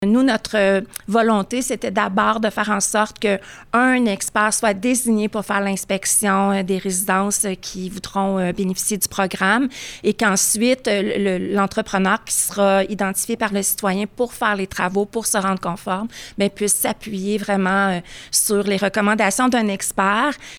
Elle en a fait l’annonce lundi lors d’une conférence de presse.
Le premier consiste à effectuer l’inspection des installations par un spécialiste qui sera accrédité par la Ville comme l’a expliqué la mairesse, Lucie Allard.
Mardi-Lucie-Allard.mp3